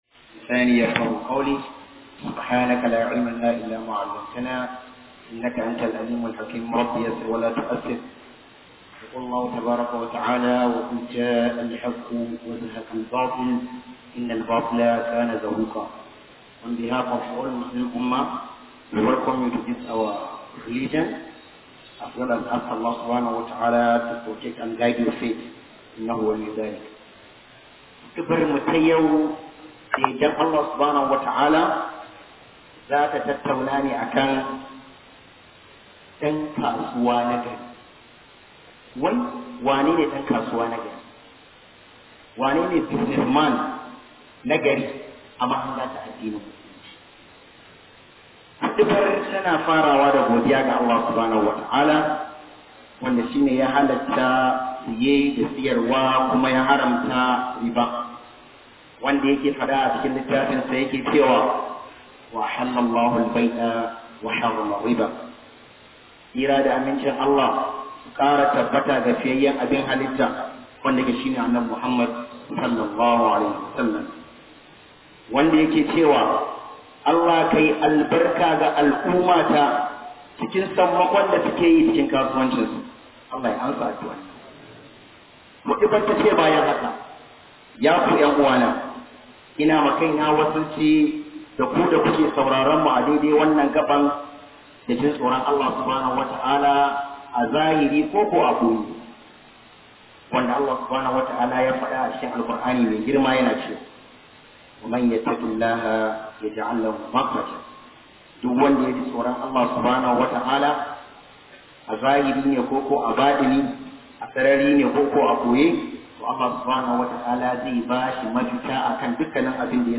KHUDBAH JUMA-A
045 Khuduba Dan Kasuwa Na Gari.mp3